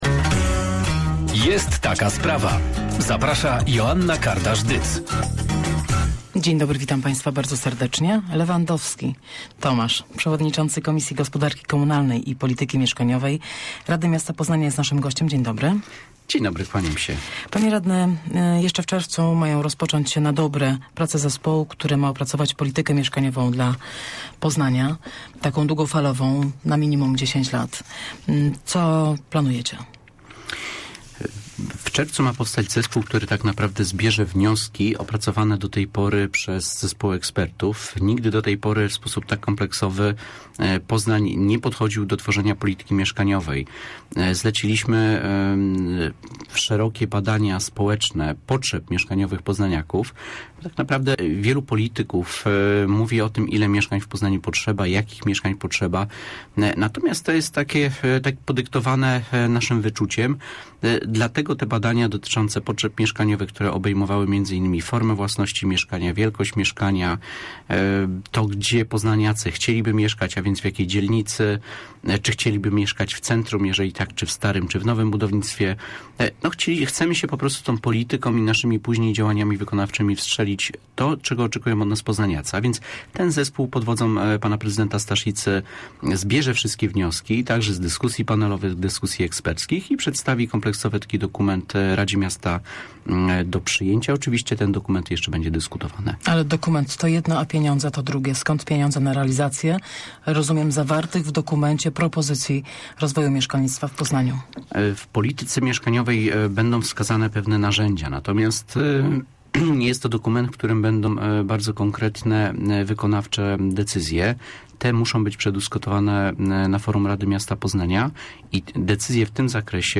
- Budową mieszkań zajmą się podlegające miastu instytucje - powiedział w porannej rozmowie Radia Merkury przewodniczący Komisji Gospodarki Komunalnej i Polityki Mieszkaniowej Rady Miasta Poznania Tomasz Lewandowski.
523fbjuz6hqb2sn_rozmowa_o_mieszkaniach_lewandowski.mp3